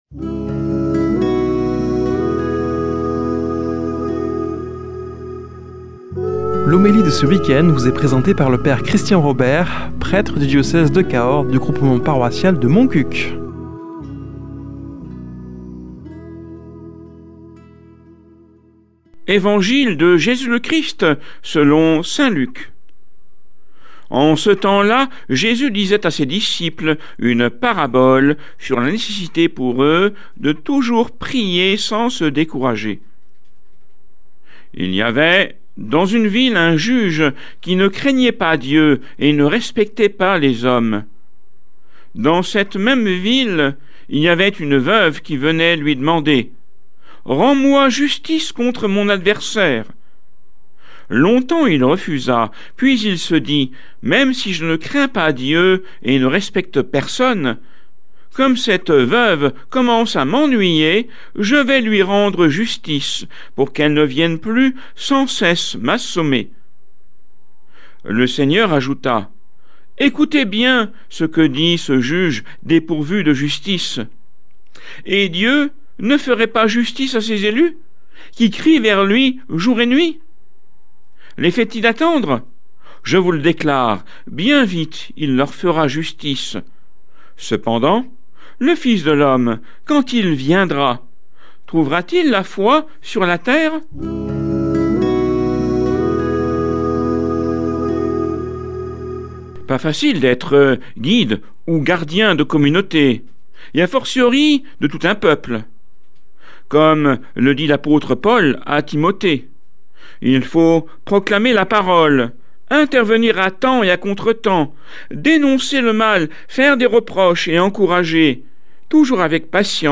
Homélie du 18 oct.